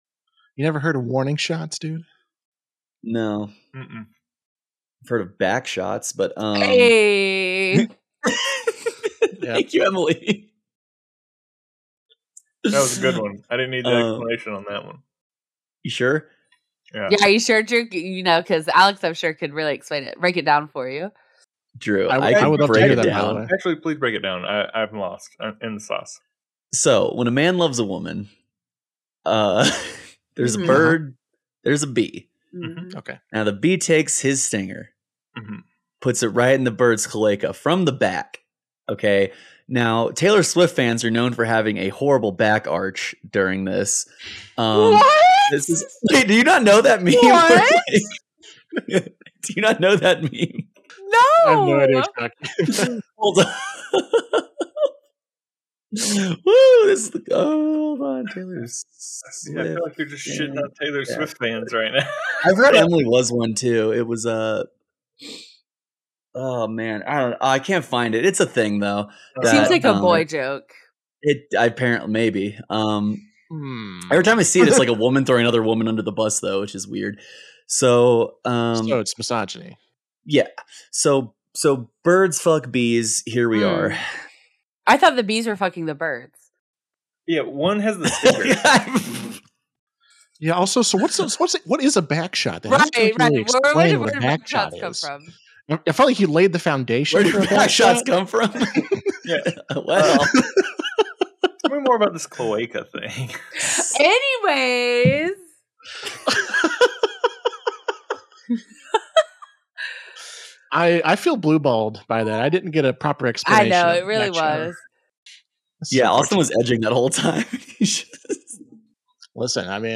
Backyard Bards is a TTRPG actual-play podcast.